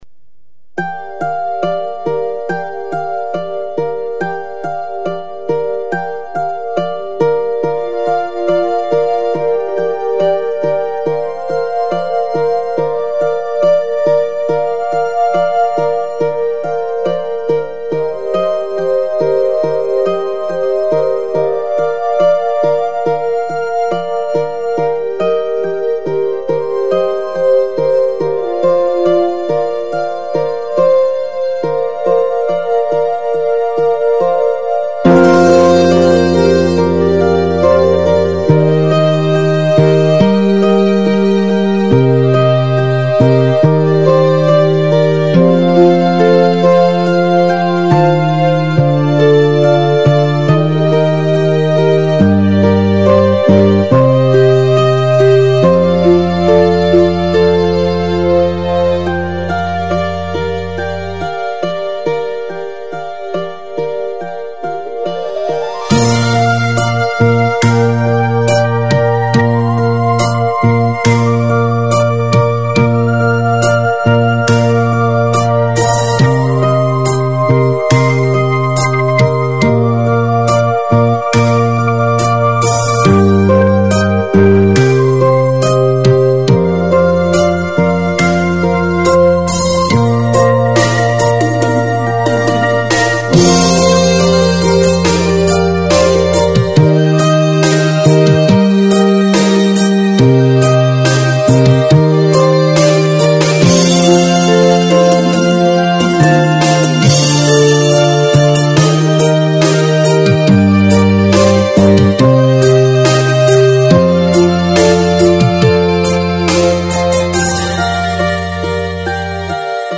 无 调式 : 降B 曲类